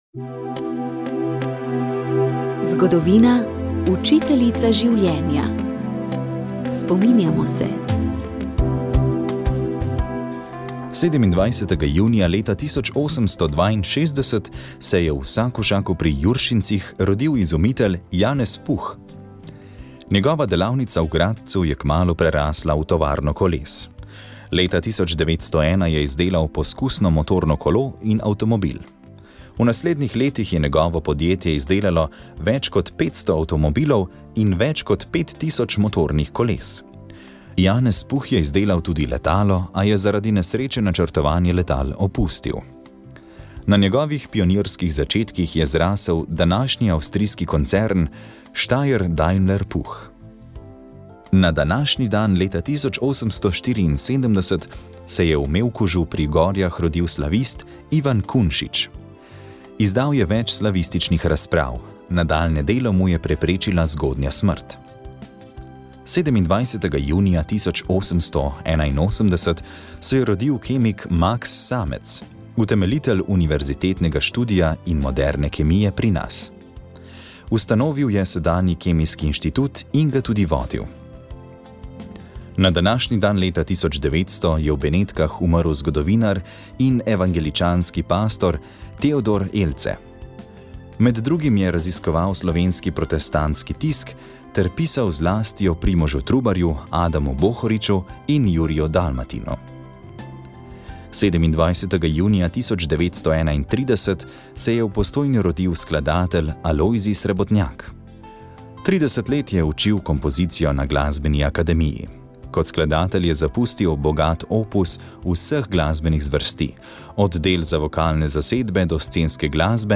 Večerno vročino lahko hladimo z mrzlo pijačo ali s klimo, mi pa vam ponujamo še osvežujočo glasbo ter osvežilni humor. Oddaja Petkov večer je tokrat butalsko obarvana.